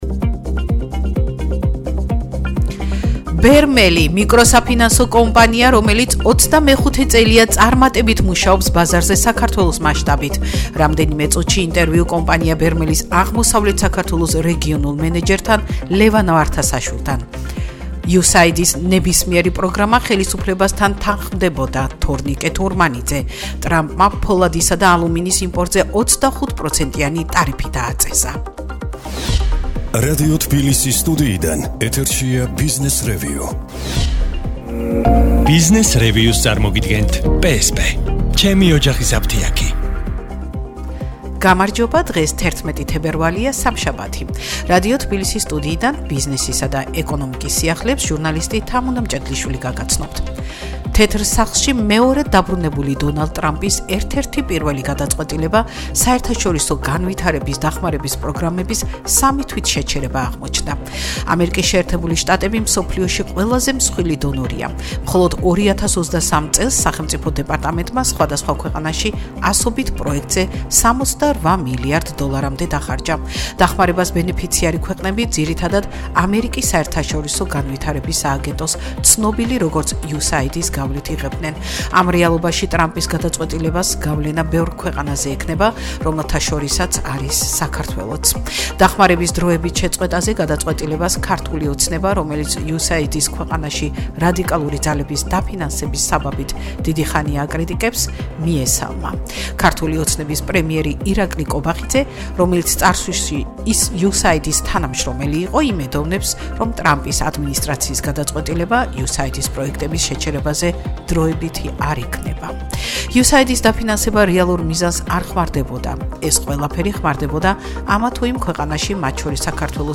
“ბიზნესრევიუ” [გადაცემა] – 11.02.2025